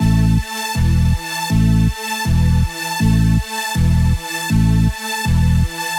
Подскажите пожалста по настройке сайдчейна. У меня почему то ноты баса дублируют ноты лида в нижней октаве?